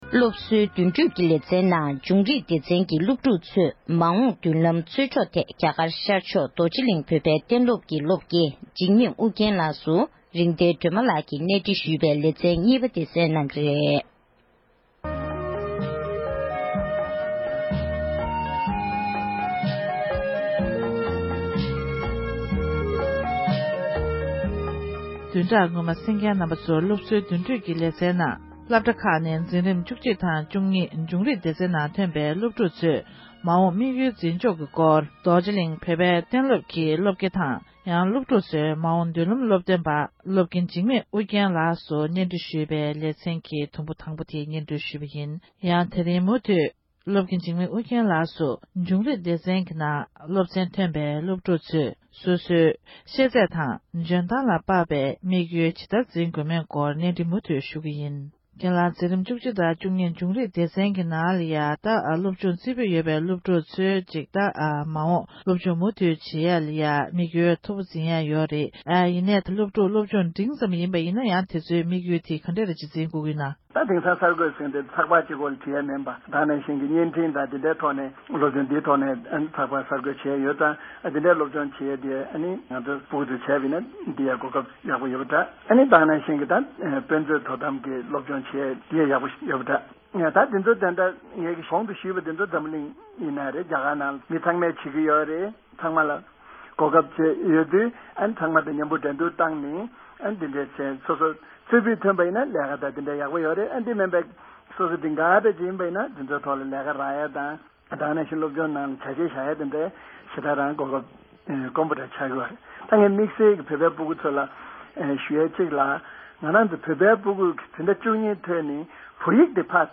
སྒྲ་ལྡན་གསར་འགྱུར།
གནས་འདྲི